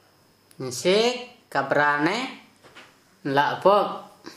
Dialect: Hill-Plains mixed/transitional